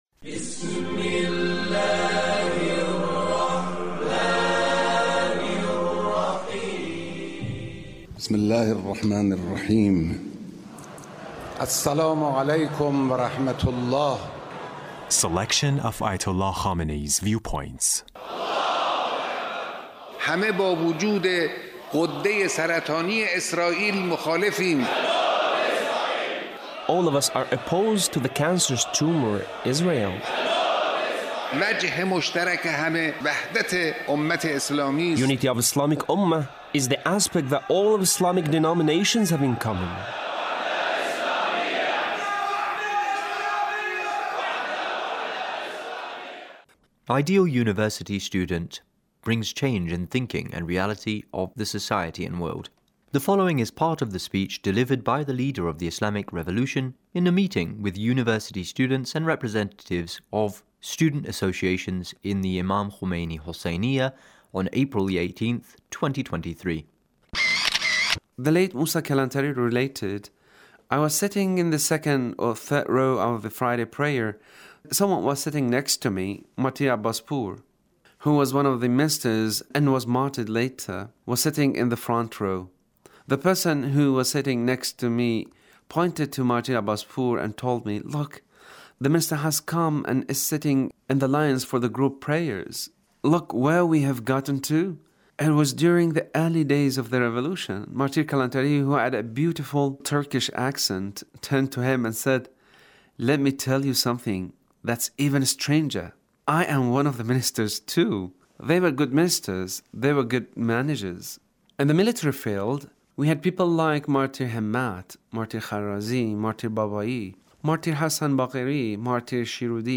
Leader's Speech with University Student